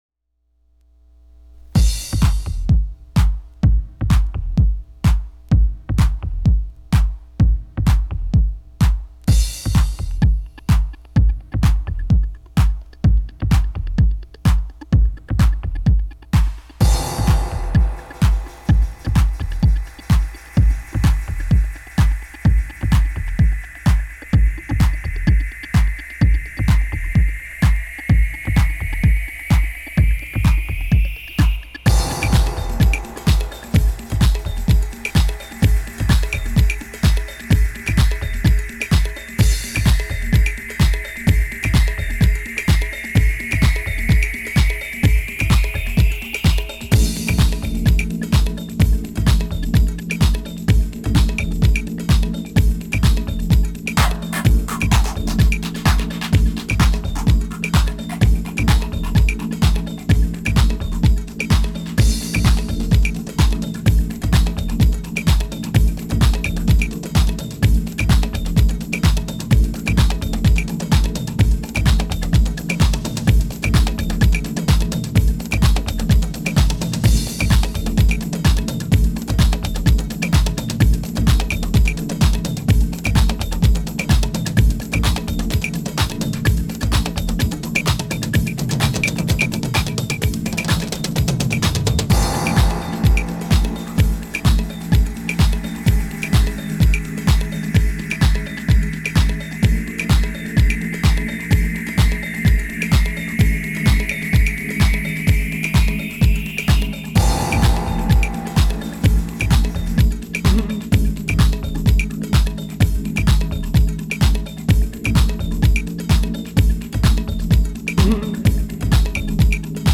This was my first mix and a great benchmark for where I was after a few months. I was still trying to figure out how I was going to combine my musical influences so the programming is all over the place. There are a few spots where I could have mixed better.